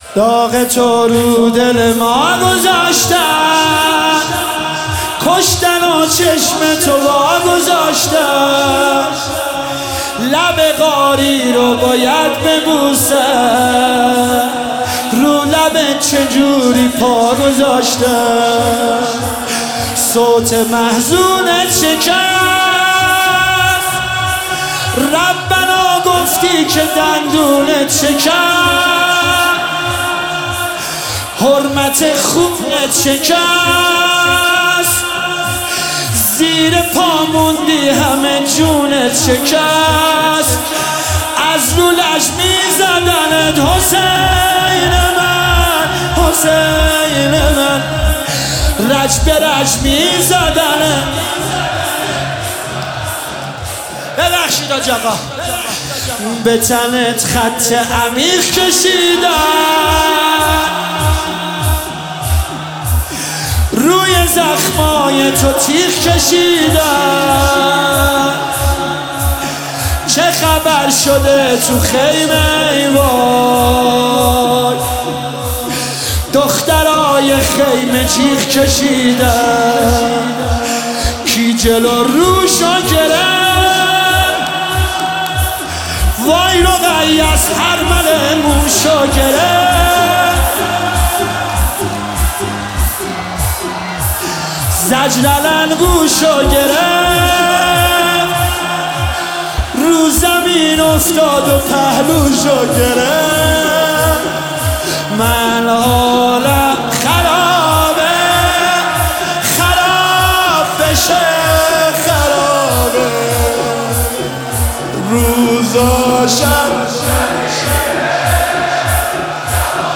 شهادت حضرت ام البنین (س) 1403